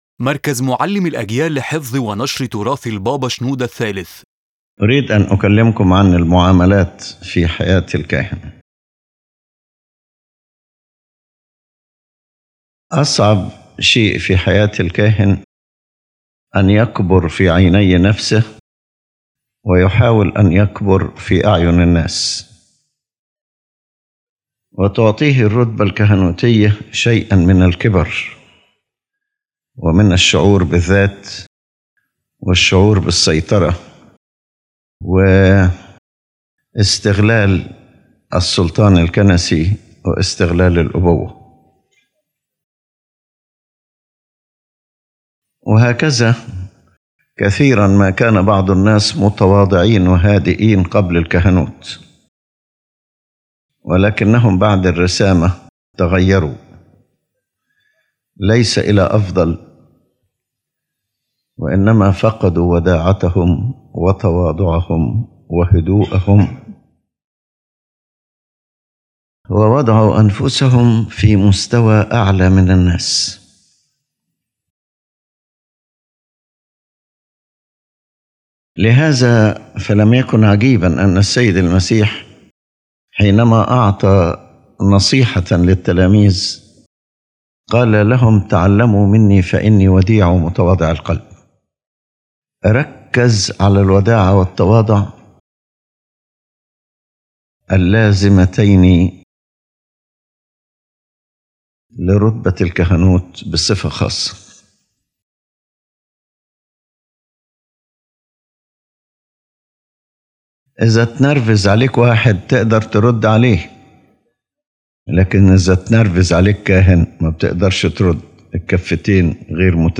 Introduction and essence of the lecture The speaker addresses the problems of conduct in the life of the priest, and affirms that the greatest danger threatening the priesthood is the exaltation of the self and the exploitation of authority.